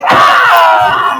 Death Moan Normal (Real)
sCreAm
moan.mp3